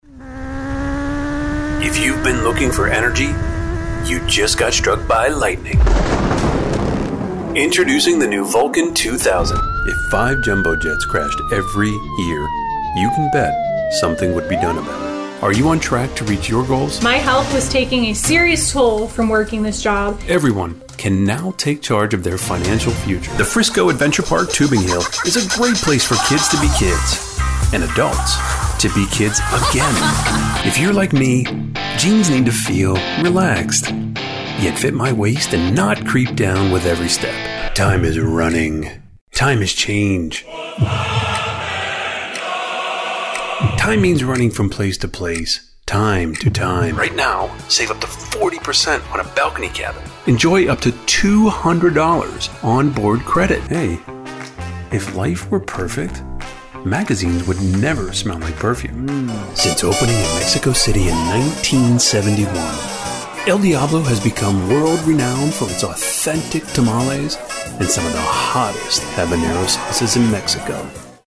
Sizzle Commercial Demo